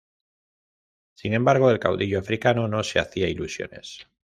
cau‧di‧llo
/kauˈdiʝo/